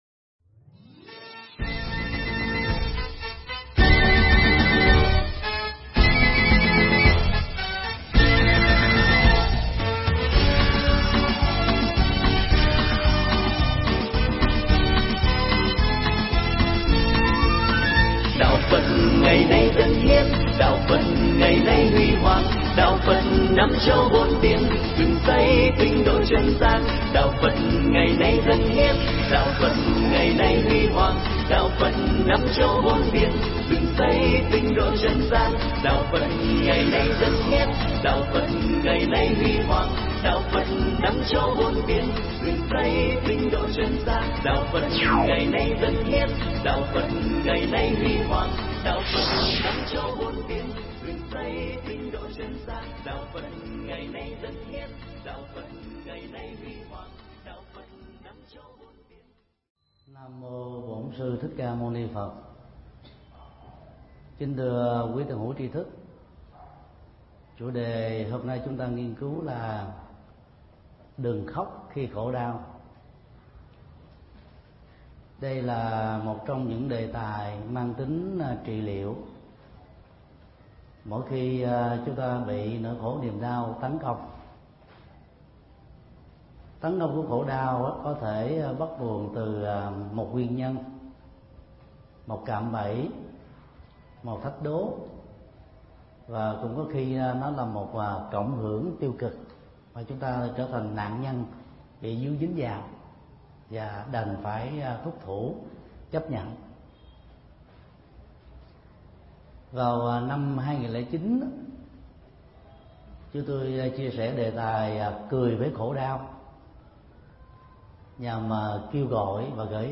Pháp âm Đừng khóc khi khổ đau
bài giảng Đừng khóc khi khổ đau
Giảng tại Địa điểm sinh hoạt tạm của chùa Giác Ngộ